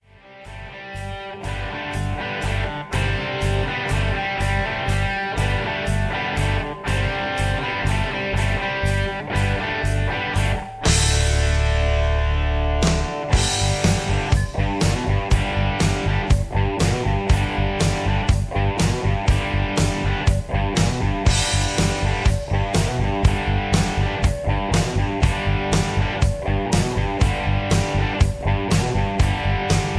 karaoke collection